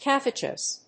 /ˈkæ.θɪ.təs(米国英語)/